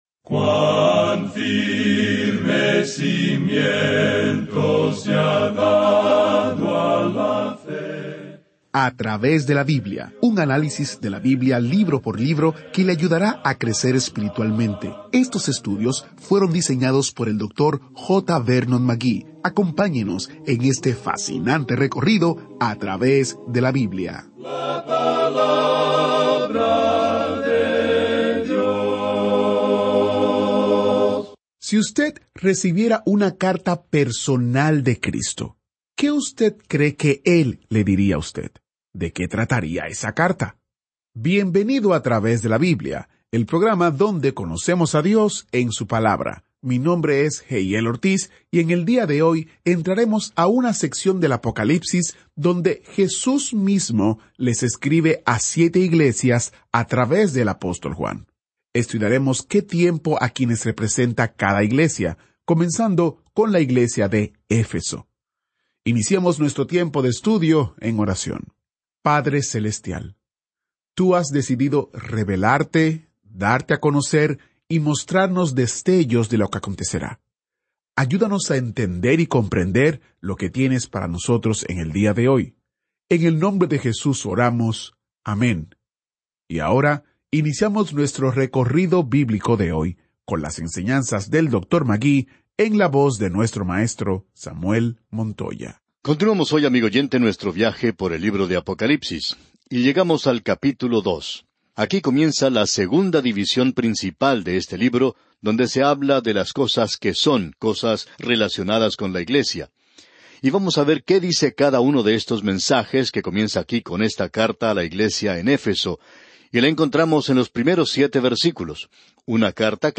Este es un programa de radio diario de 30 minutos que sistemáticamente lleva al oyente a través de toda la Biblia.